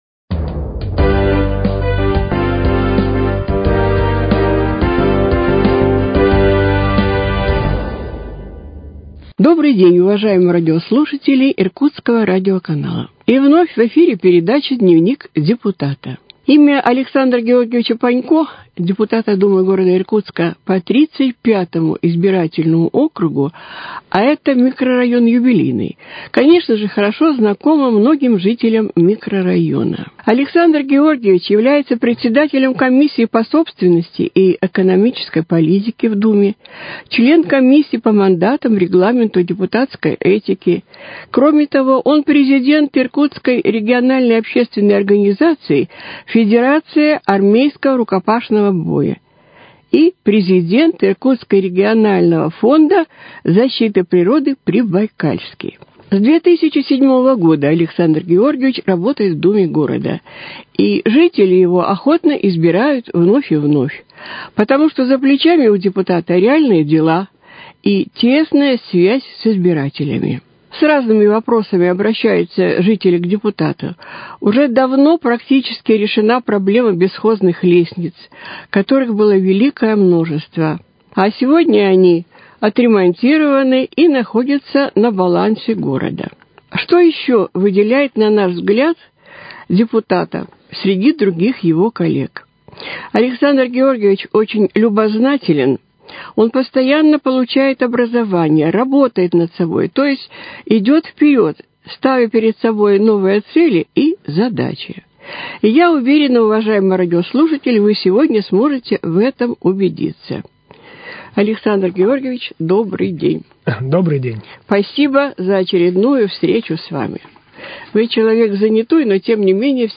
С депутатом Думы Иркутска по 35 избирательному округу Александром Панько беседует